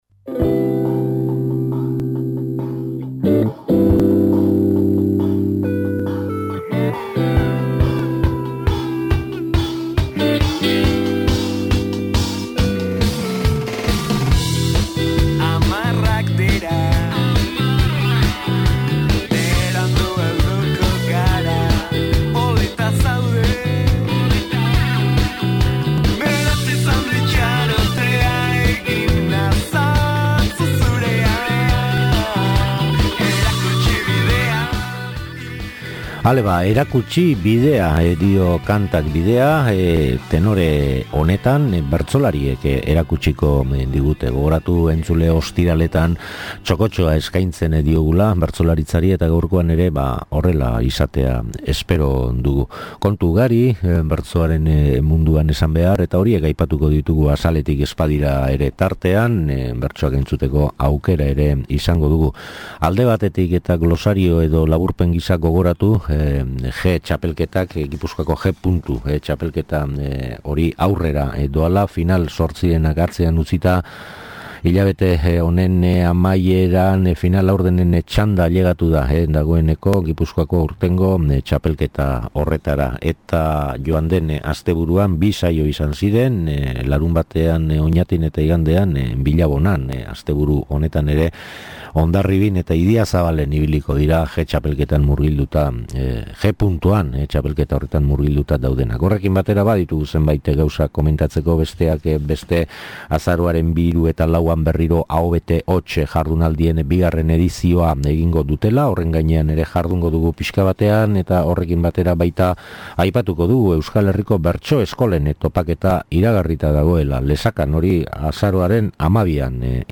SOLASALDIA
Gpuntua gipuzkoako txapelketaren lehen final erdien aipamenak (Oñati- Billabona) eta bertan kantatutakoaren zenbait lagin entzungai dira gaurko emanaldian. Bestelako informaziorik ere bada: “Aho bete hots” jardunaldiei buruzkoa edota agenda.